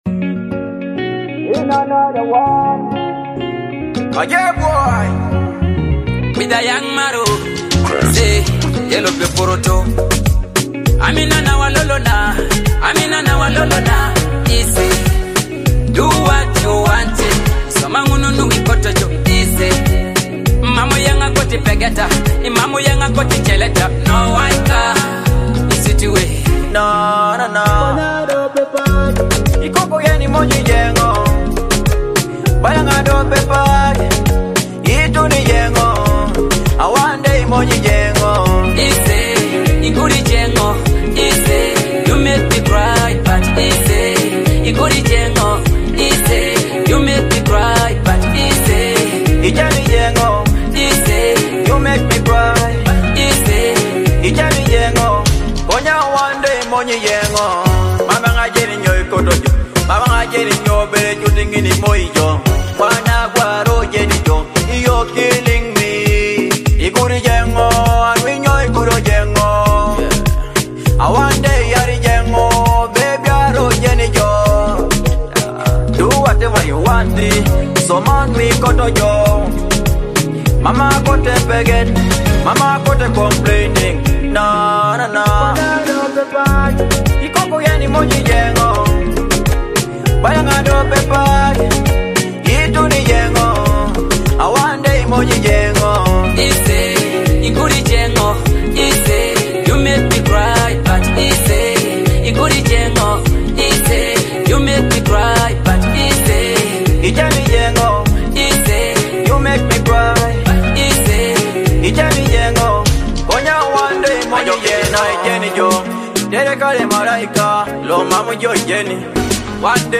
With energetic dancehall rhythms and dynamic vocals
and vibrant dancehall vibes.